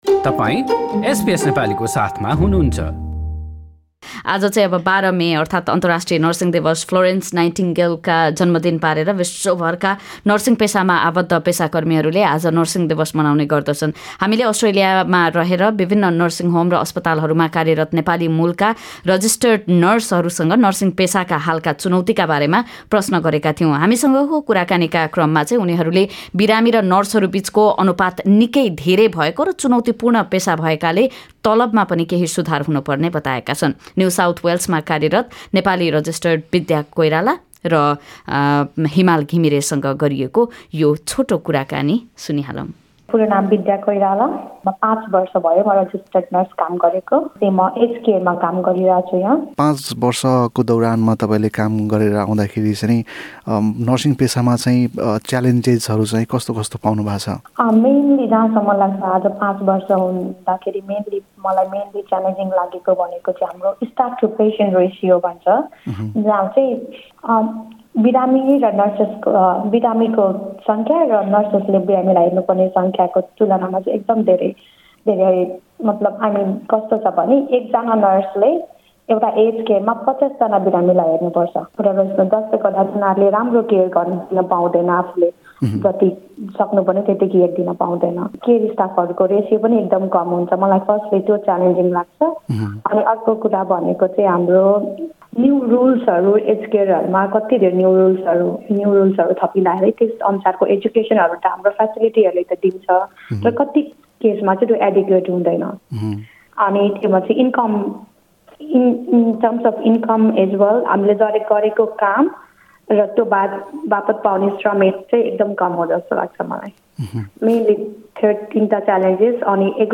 नेपाली मूलका रजिस्टर्ड नर्सहरूसँग गरिएको कुराकानी सुन्नुहोस्।